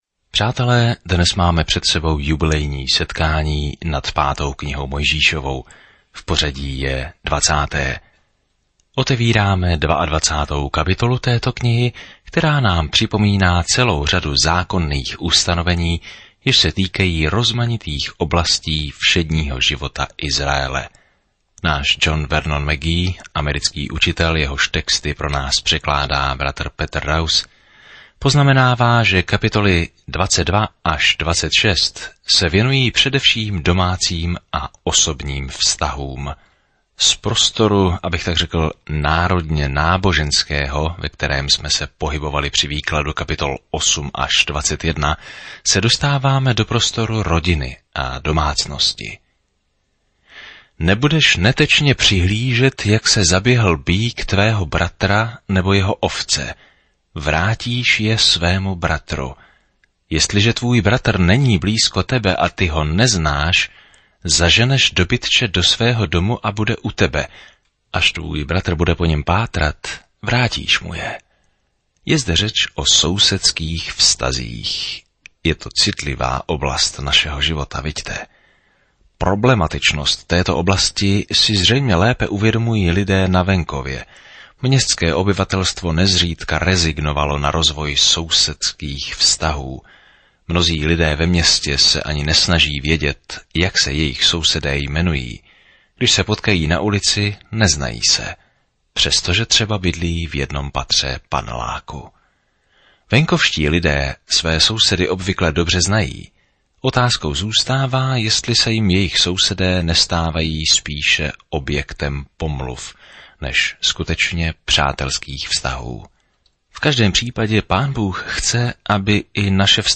Písmo Deuteronomium 21 Deuteronomium 22 Deuteronomium 23:1-2 Den 19 Začít tento plán Den 21 O tomto plánu Deuteronomium shrnuje dobrý Boží zákon a učí, že poslušnost je naší odpovědí na jeho lásku. Denně procházejte Deuteronomium a poslouchejte audiostudii a čtěte vybrané verše z Božího slova.